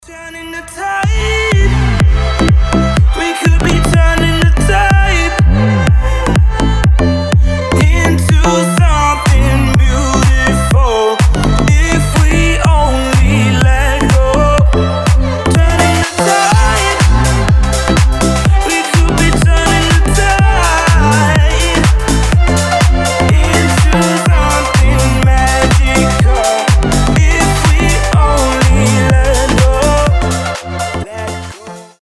• Качество: 320, Stereo
позитивные
громкие
красивый мужской голос
скрипка
house
Классная хаус музыка